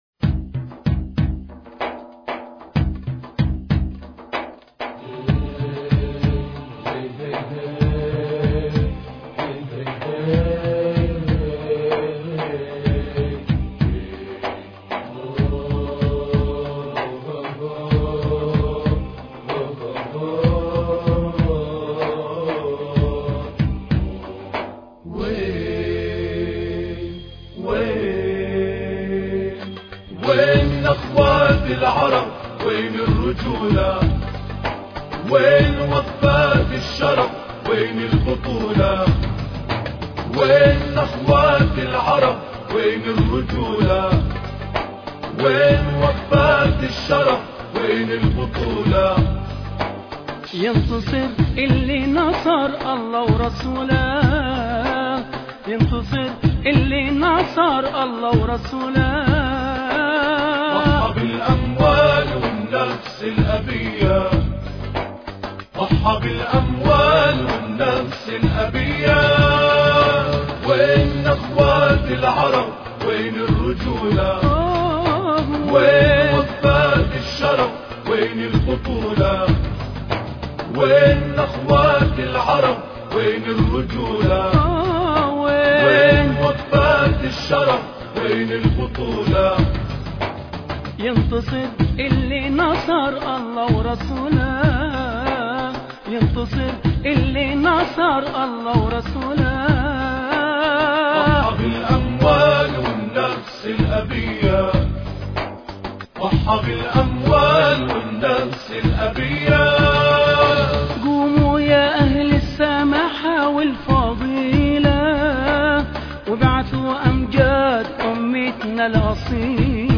نخوات العرب الثلاثاء 27 يناير 2009 - 00:00 بتوقيت طهران تنزيل الحماسية شاركوا هذا الخبر مع أصدقائكم ذات صلة الاقصى شد الرحلة أيها السائل عني من أنا..